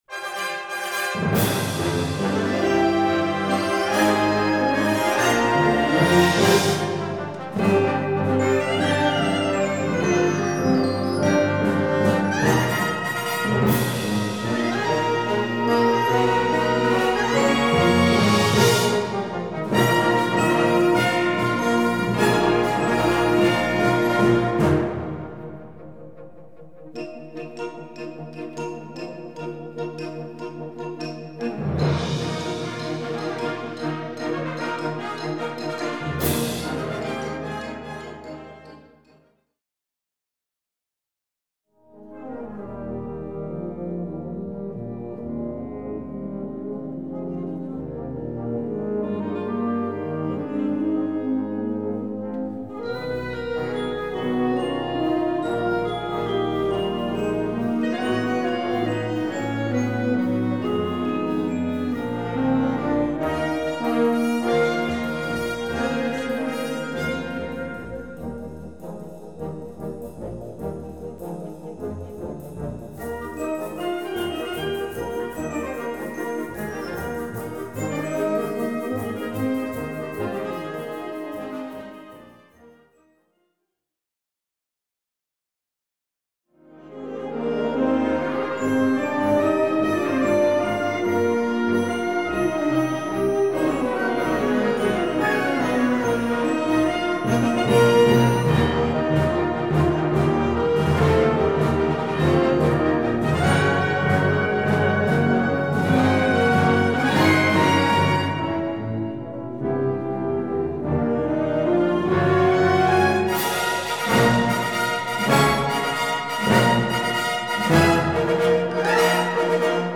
Kategorie Blasorchester/HaFaBra
Unterkategorie Ouvertüre (Originalkomposition)
Besetzung Ha (Blasorchester)